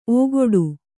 ♪ ōgoḍu